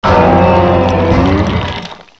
sovereignx/sound/direct_sound_samples/cries/ting_lu.aif at 6b8665d08f357e995939b15cd911e721f21402c9
ting_lu.aif